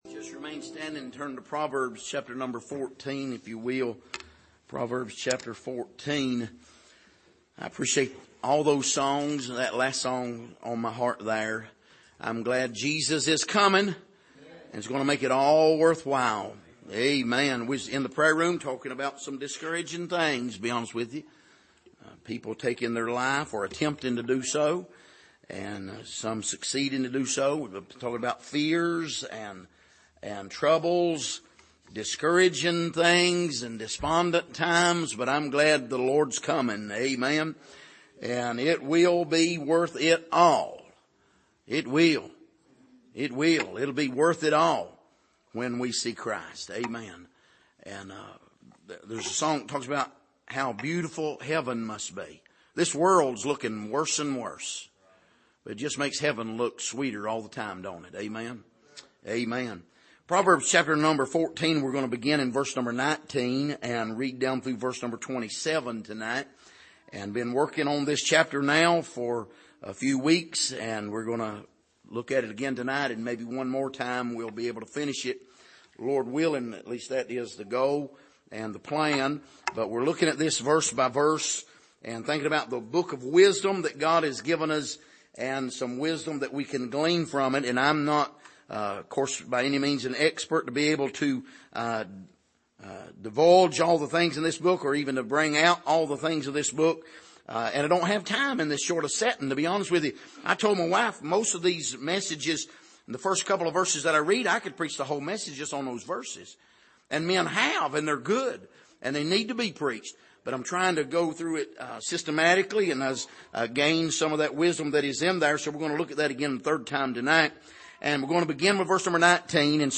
Passage: Romans 3:21-31 Service: Sunday Morning